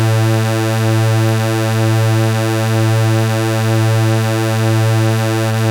MOOG TUNE.wav